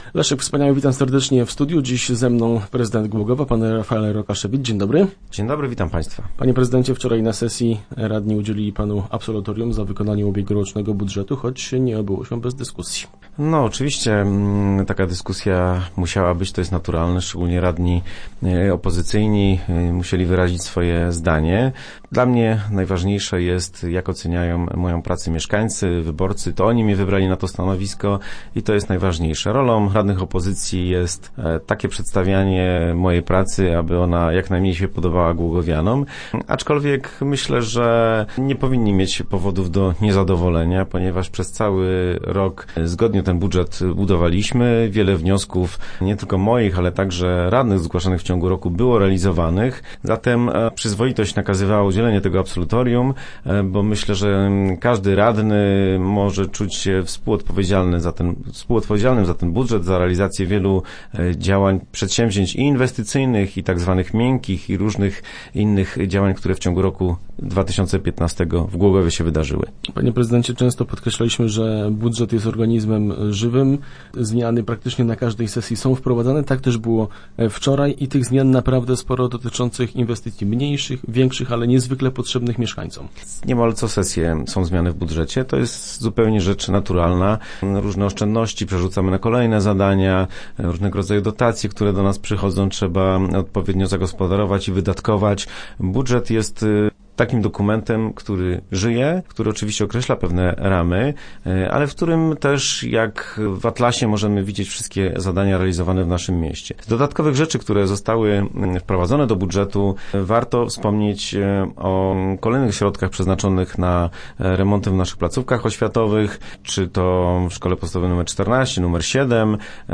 Start arrow Rozmowy Elki arrow Absolutorium dla prezydenta
Przyjęto też ważne uchwały oraz zmiany w budżecie. O tym w radiowym studiu mówił nam w środę Rafael Rokaszewicz.